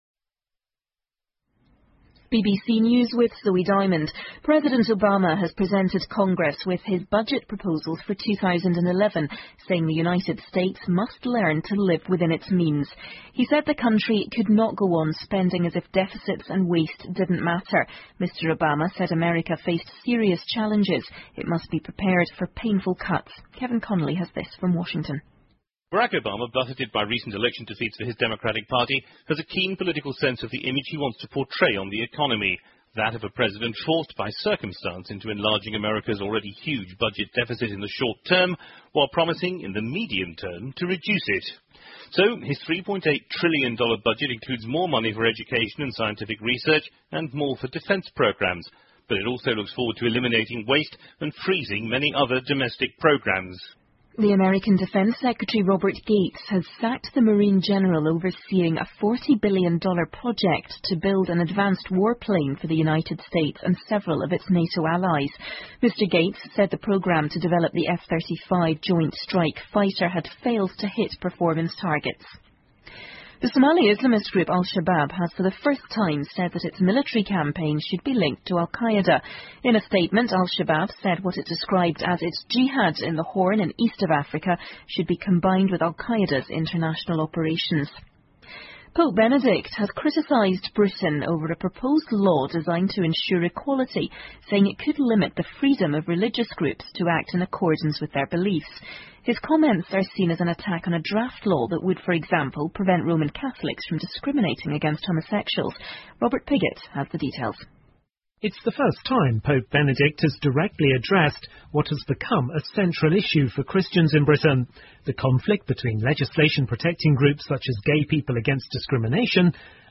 英国新闻听力 德国决意"私买"银行逃税资料 听力文件下载—在线英语听力室